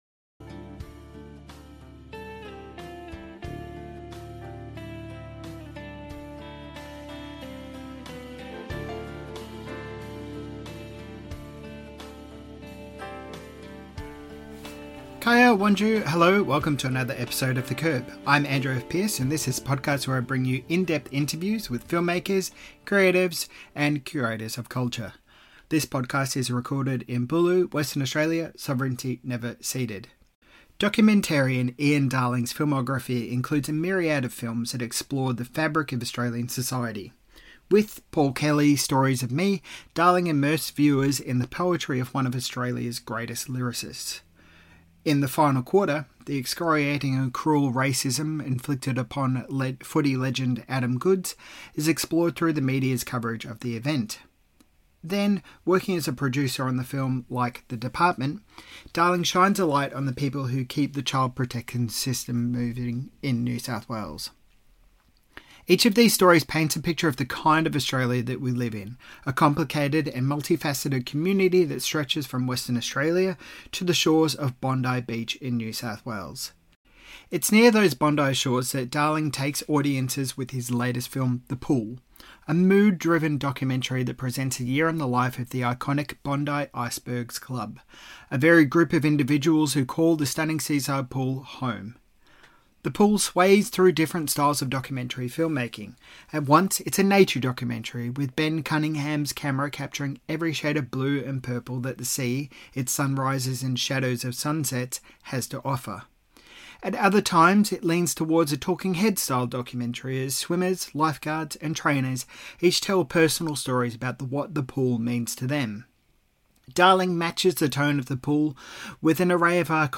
Adelaide Film Festival Interview